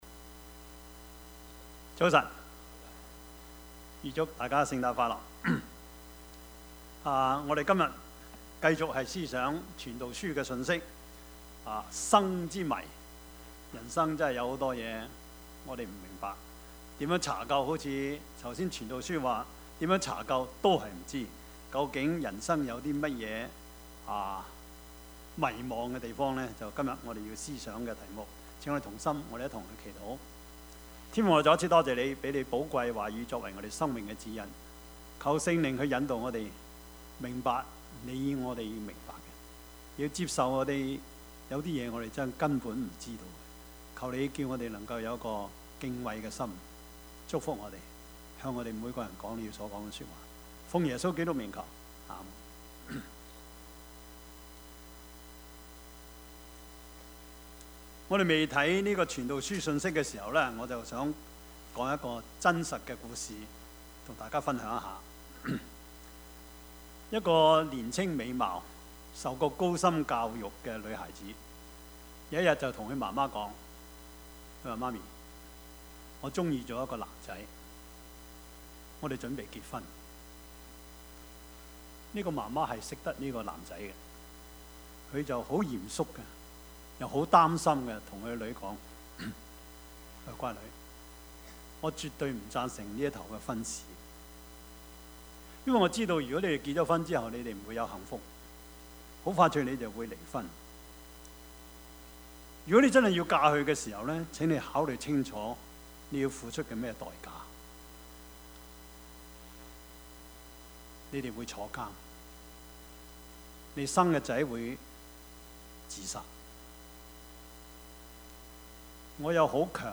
Service Type: 主日崇拜
Topics: 主日證道 « 預備他的道路 為我行了大事 »